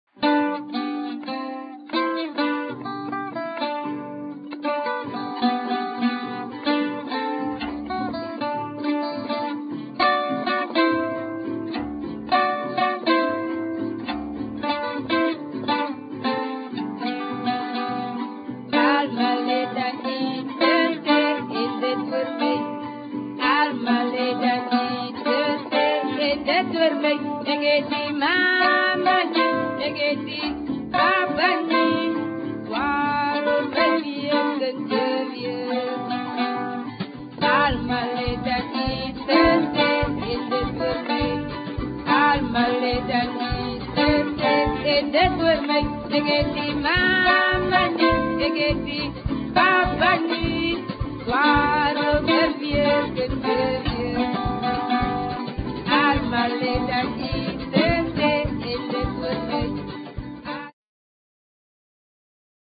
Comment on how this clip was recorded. live at the Baxter Theatre Capetown